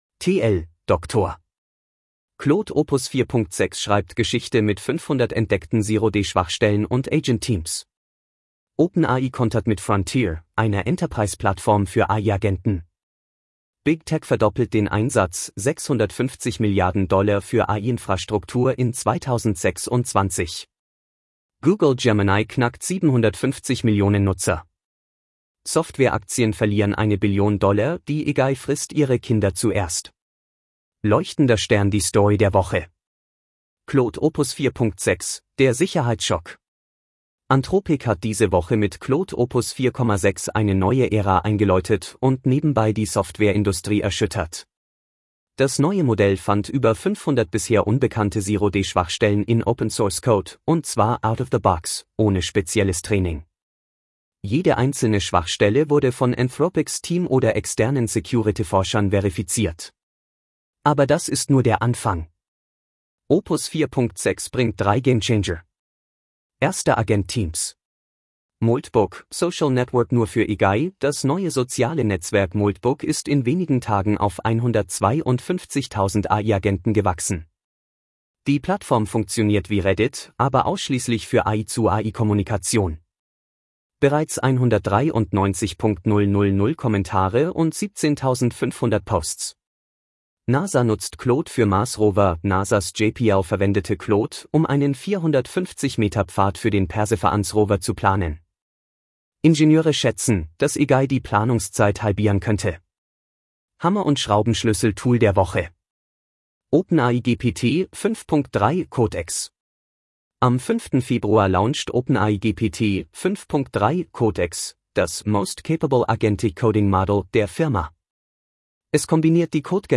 Vorgelesen mit edge-tts (de-DE-ConradNeural)